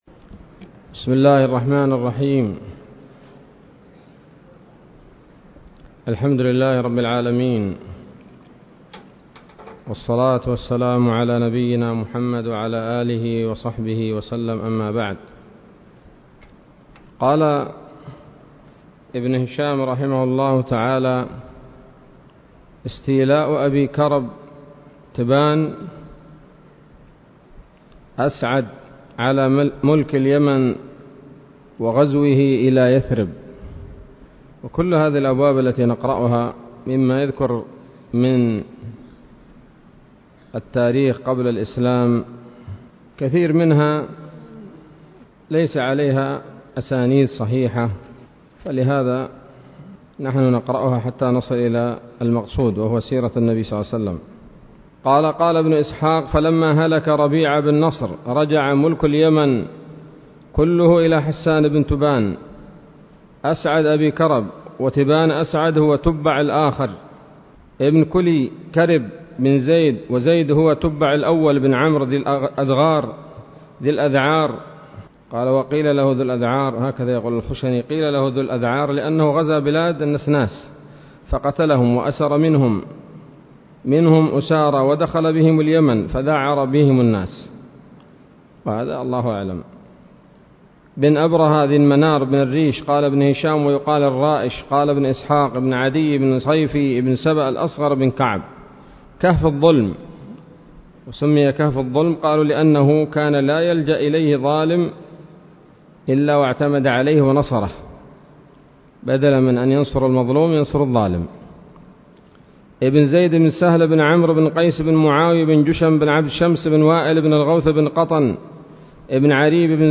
الدرس الرابع من التعليق على كتاب السيرة النبوية لابن هشام